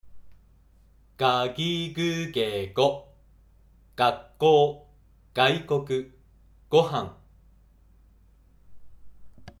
濁音について
が行の濁音は、息を破裂させて出す破裂音はれつおんです。
か行の「k（無声音）」に、声帯の響きを入れた「g（有声音）」が子音になります。
言葉の始めに位置していて、鼻をつまんでも出る音です。